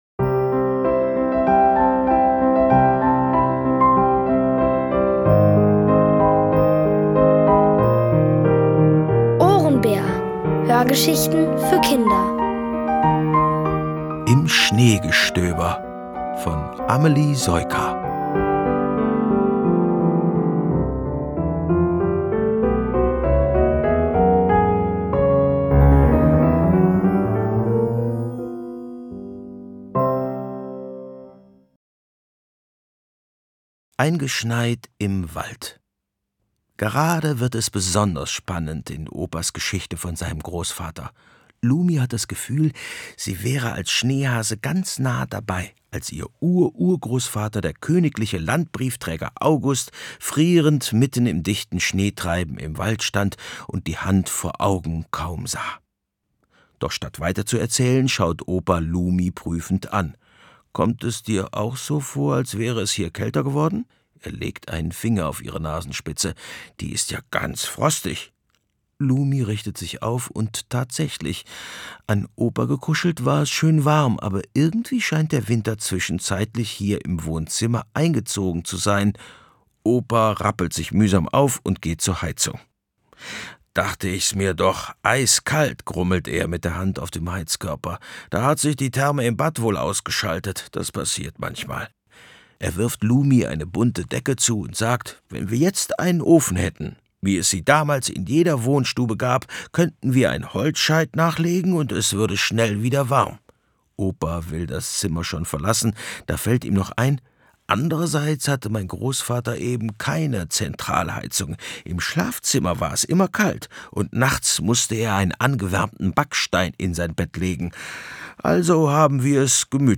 Von Autoren extra für die Reihe geschrieben und von bekannten Schauspielern gelesen.
Es liest: Bernhard Schütz.